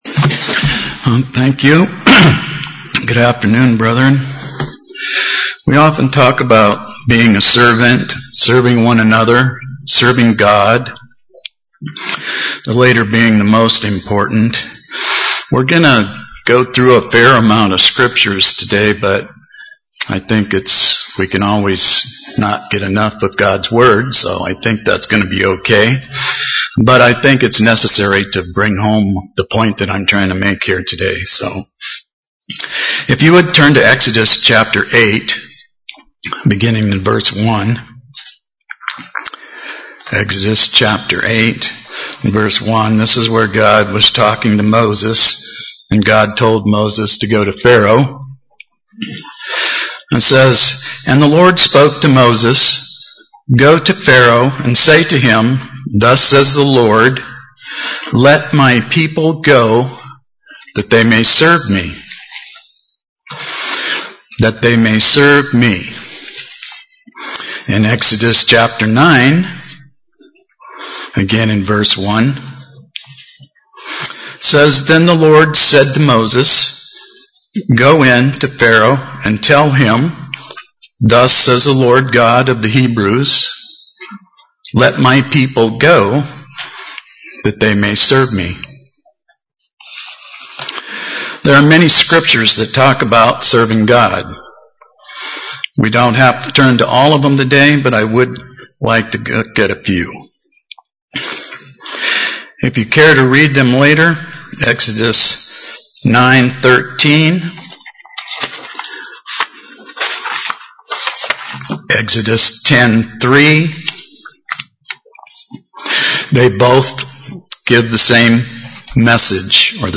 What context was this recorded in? Given in Burlington, WA